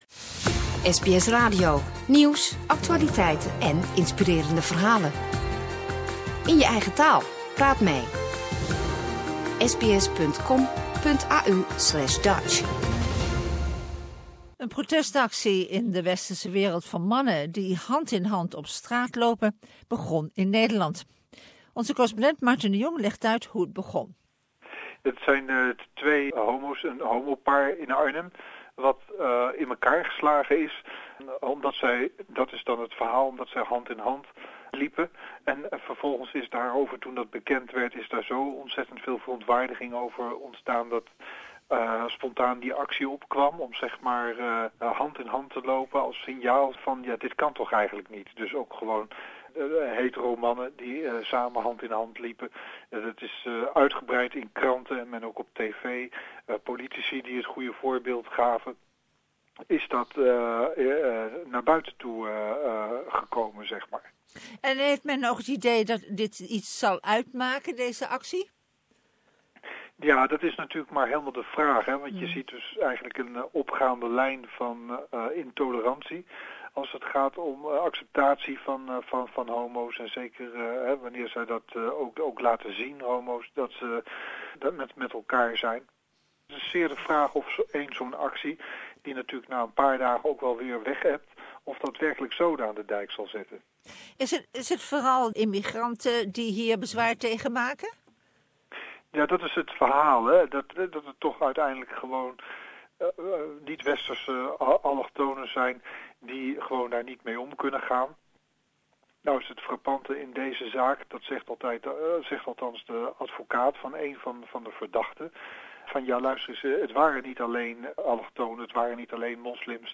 Dutch correspondent